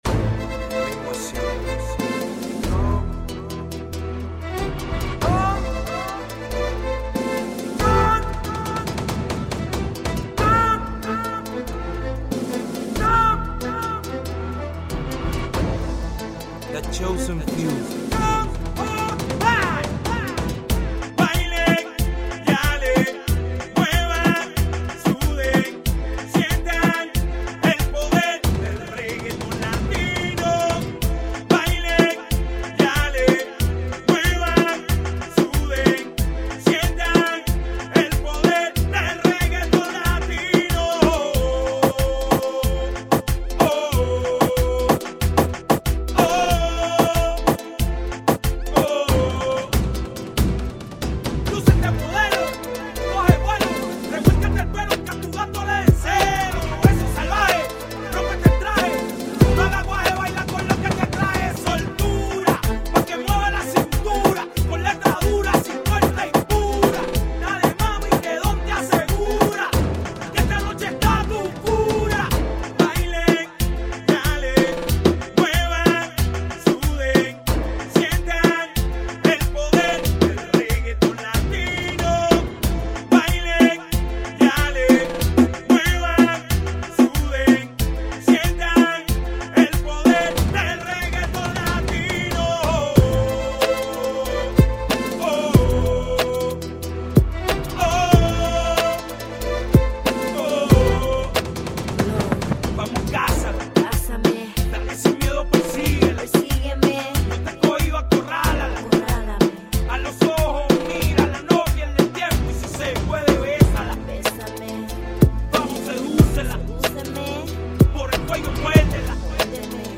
Музыка в стиле Reggaeton